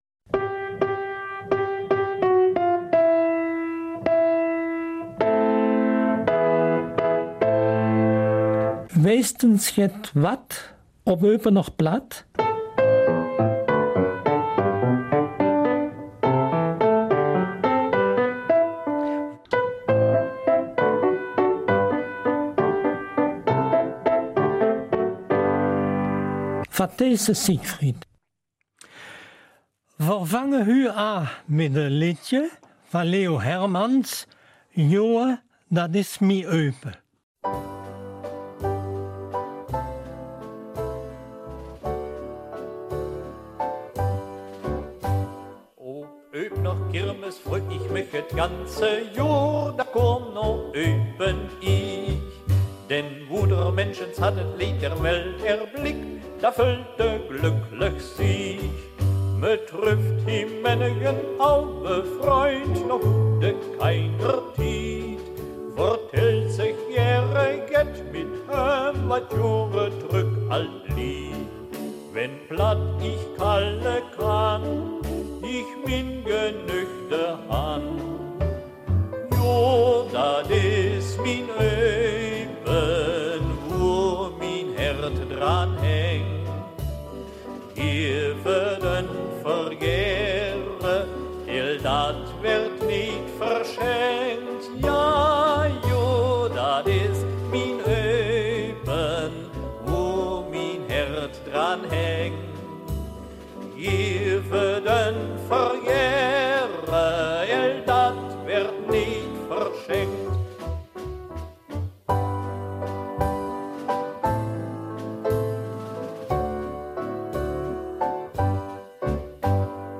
Eupener Mundart - 13.